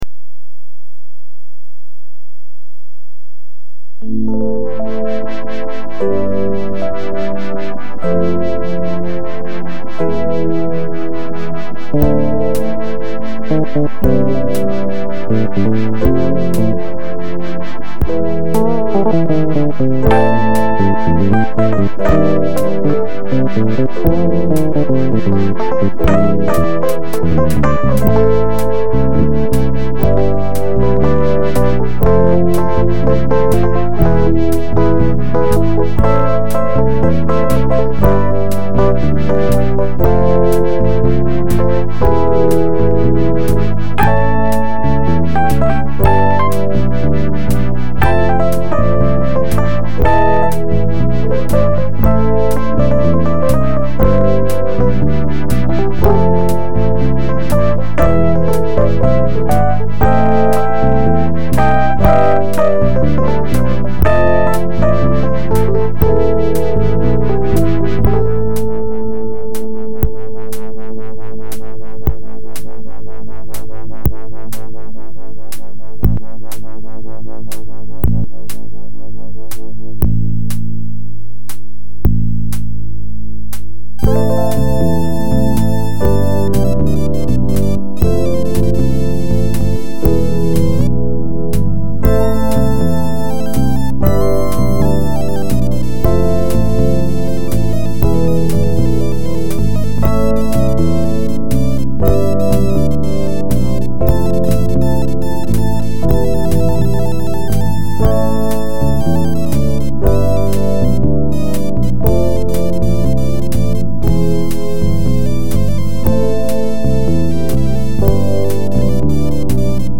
lab Yamaha DX 7